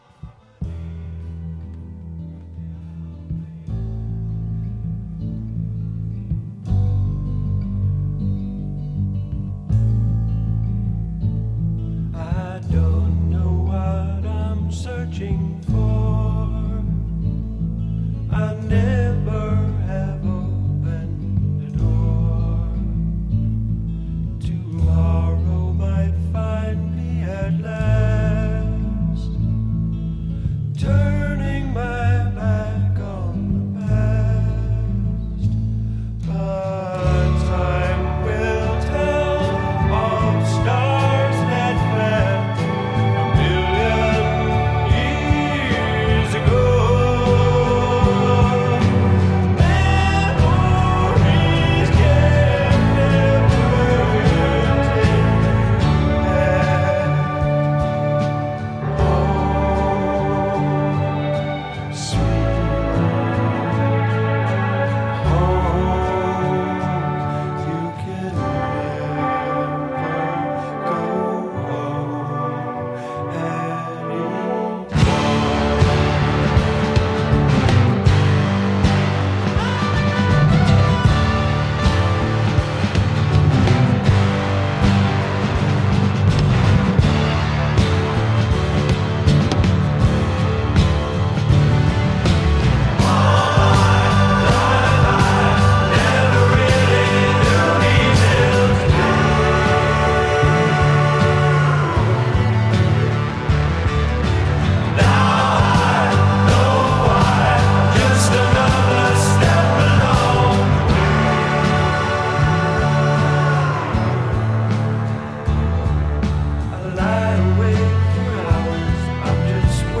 pastoral, flute-and-mellotron infused progressive rock